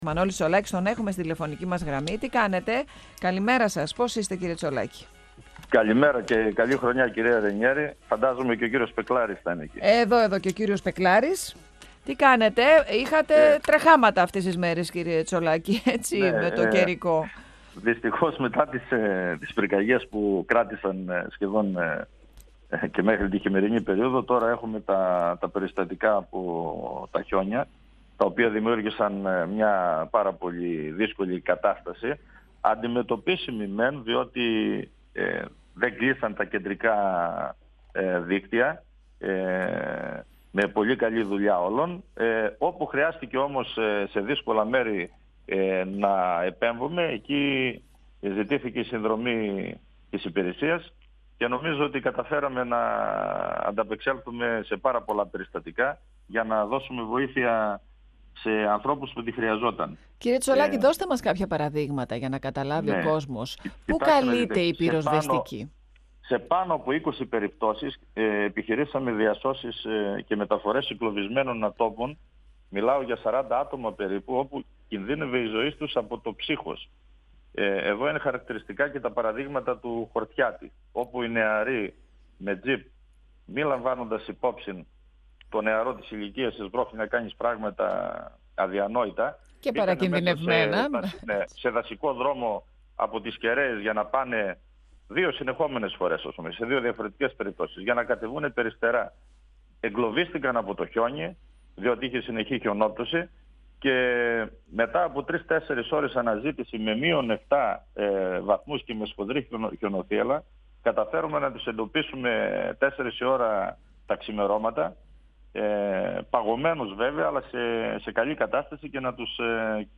Ο Μανώλης Τσολάκης ταξίαρχος Πυροσβεστικής Κεντρικής Μακεδονίας αναφέρθηκε στα περιστατικά αυτά, μιλώντας στον 102FM του Ραδιοφωνικού Σταθμού Μακεδονίας της ΕΡΤ3.
Ο Μανώλης Τσολάκης ταξίαρχος Πυροσβεστικής Κεντρικής Μακεδονίας αναφέρθηκε στα περιστατικά αυτά, μιλώντας στον 102FM του Ραδιοφωνικού Σταθμού Μακεδονίας της ΕΡΤ3. 102FM Συνεντεύξεις ΕΡΤ3